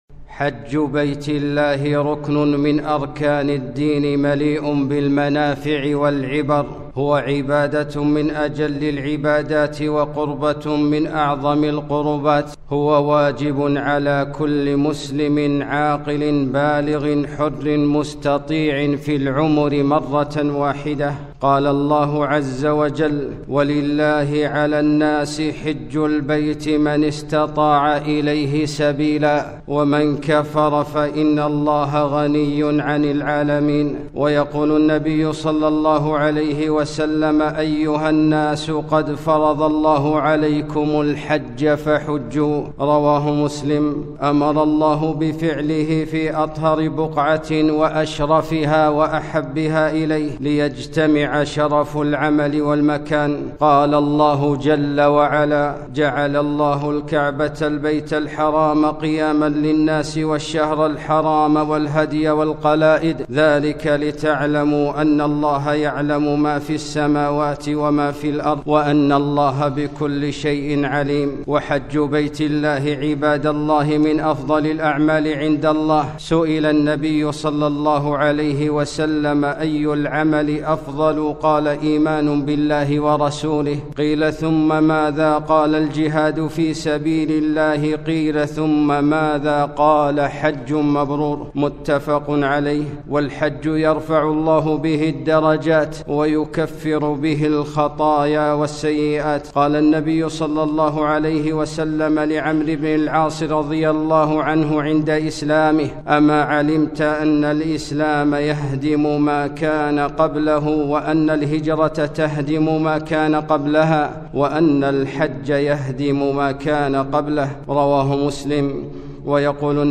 خطبة - تعجلوا إلى الحج